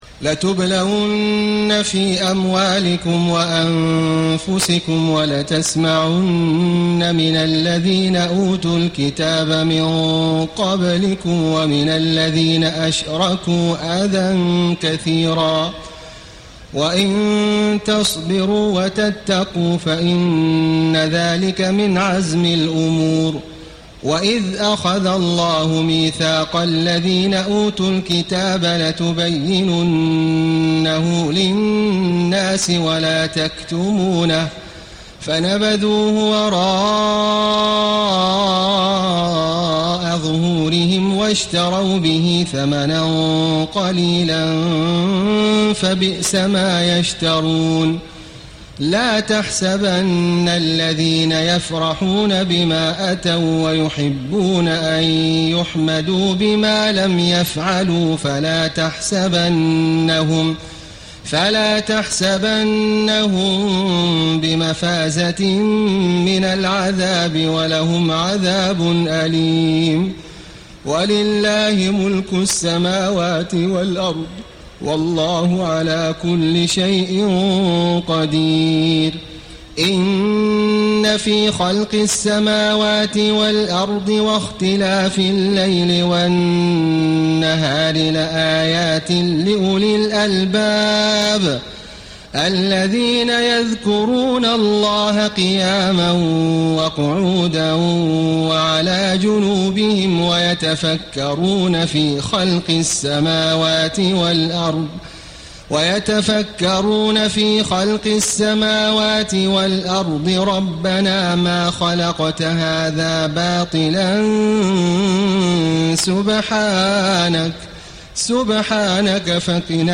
تهجد ليلة 24 رمضان 1434هـ من سورتي آل عمران (186-200) و النساء (1-22) Tahajjud 24 st night Ramadan 1434H from Surah Aal-i-Imraan and An-Nisaa > تراويح الحرم المكي عام 1434 🕋 > التراويح - تلاوات الحرمين